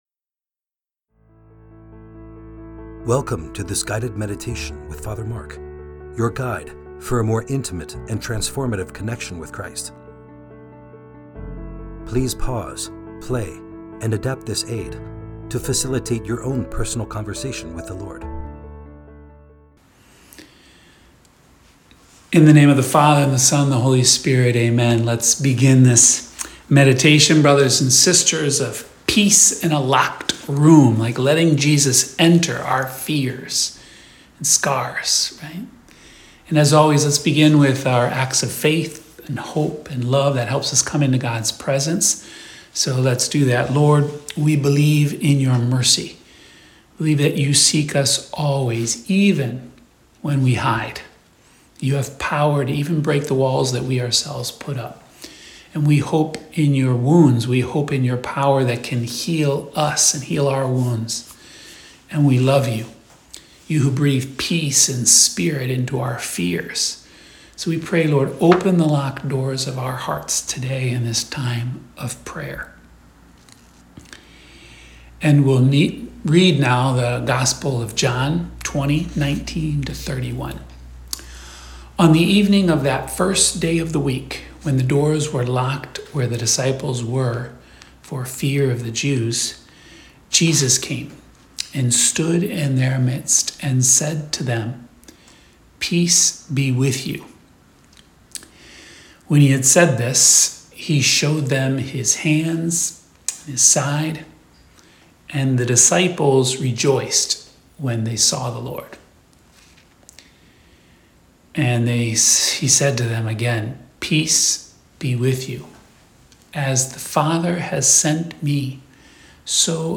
Friday Meditation